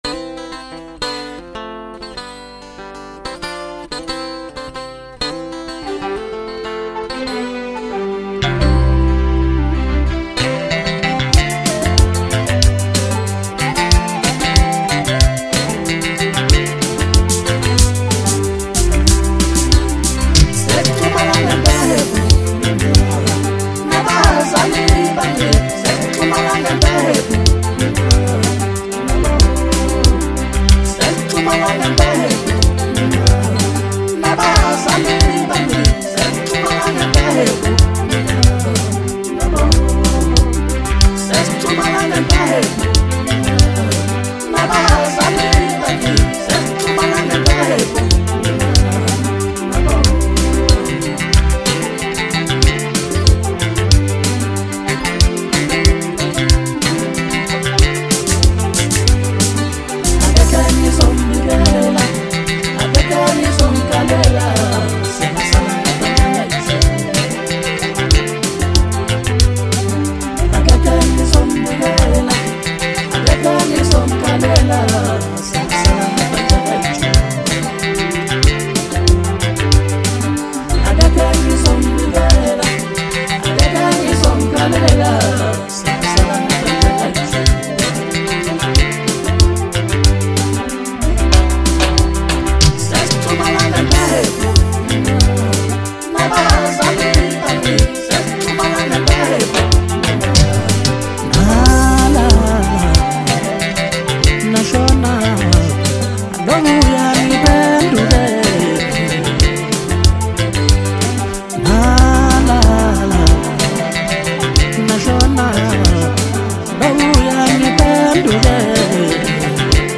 • Genre: Maskandi